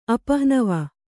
♪ apahnava